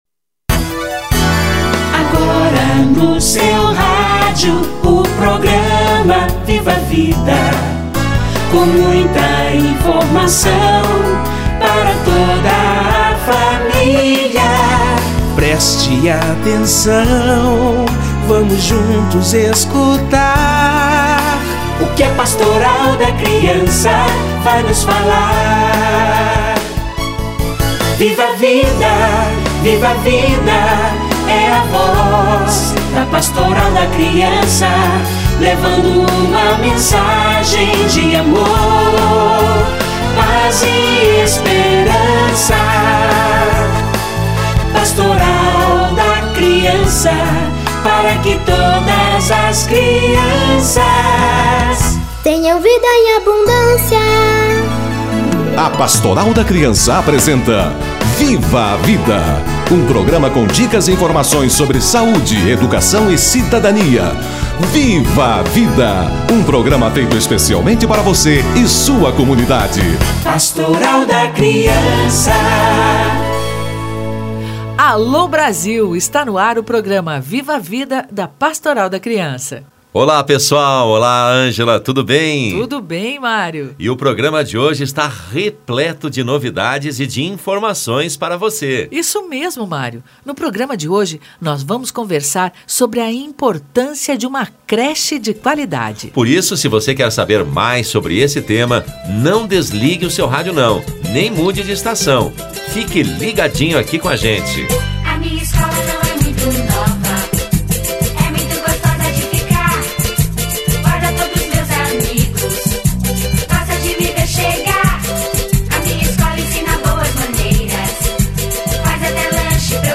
A qualidade das creches para o desenvolvimento infantil - Entrevista